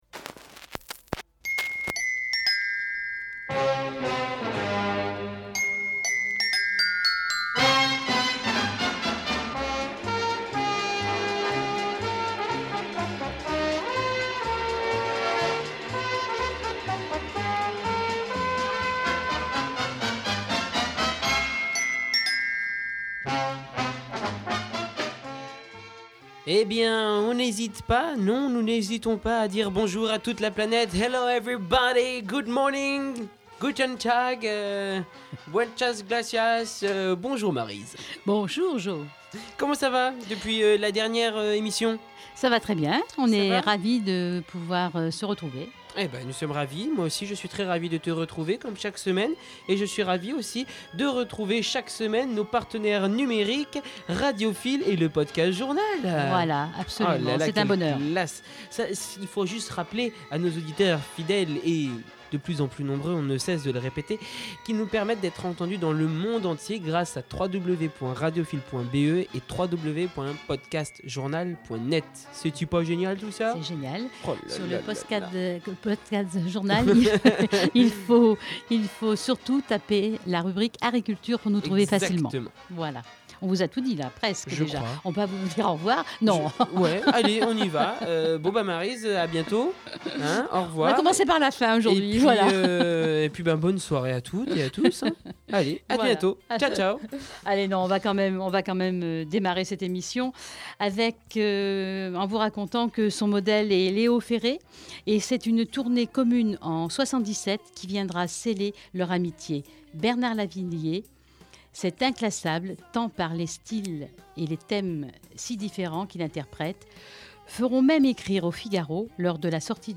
EMISSION PODCAST - 33/45, une émission musicale pas tout à fait comme les autres
Et voici déjà le dernier titre de notre 33/45 qui est chanté par un trio vocal et quel trio !